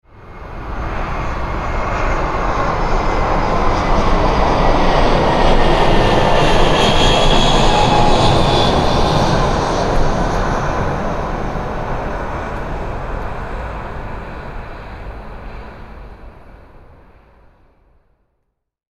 Catégorie: Bruitages